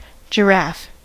Uttal
US: IPA : [ʤə.ˈɹæf] UK: IPA : /dʒɪ.ˈɹɑːf/